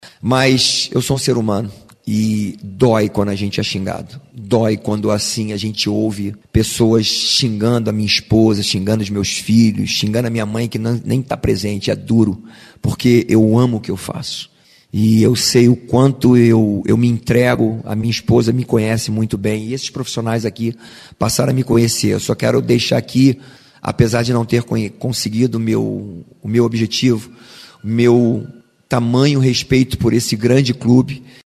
No início da entrevista coletiva que marcou esta despedida